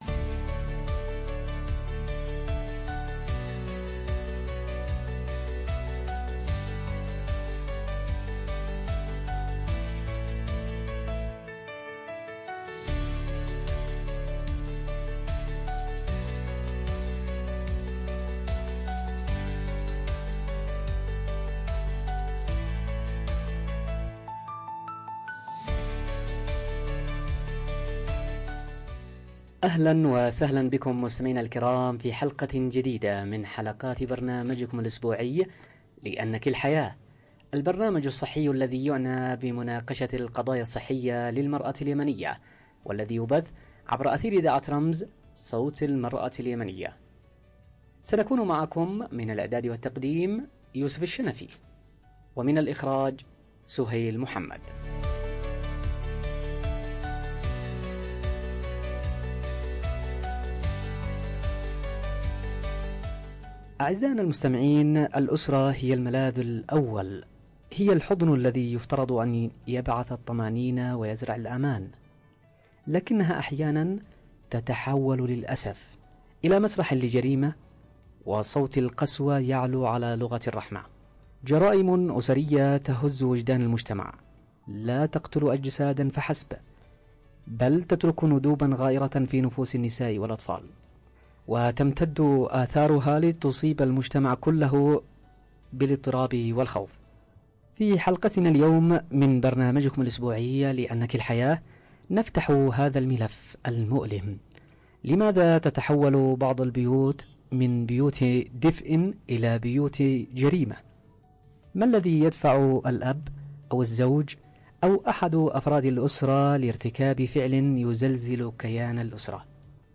📻 عبر أثير إذاعة رمز